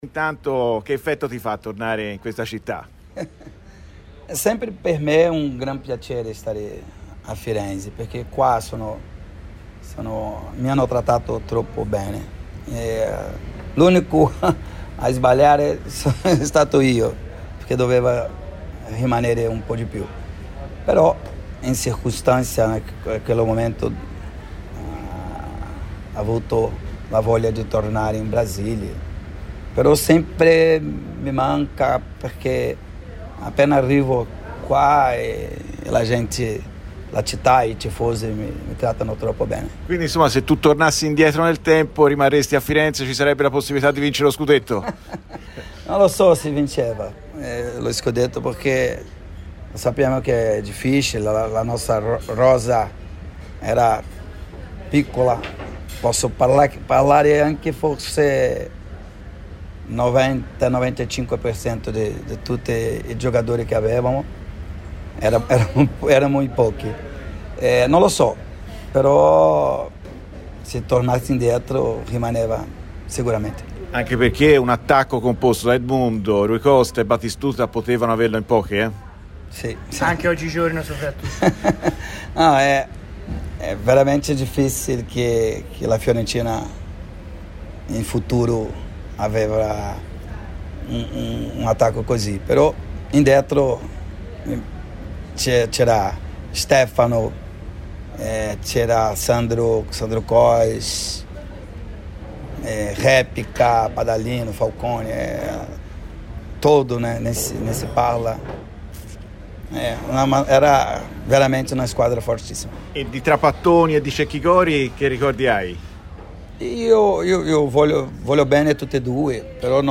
O' Animal è a Firenze e parla in esclusiva a Firenzeviola e Radio Firenzeviola.